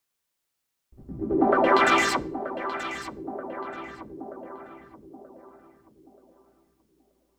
synthFX.wav